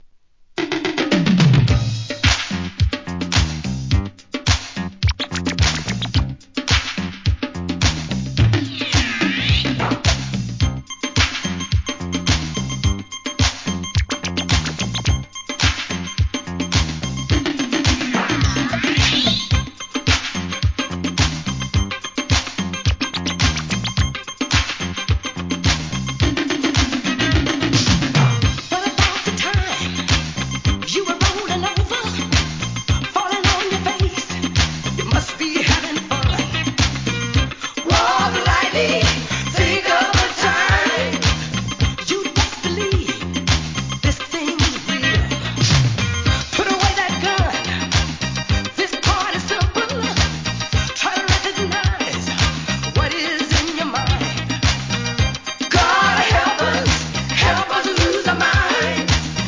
SOUL/FUNK/etc...
CLUB VERSION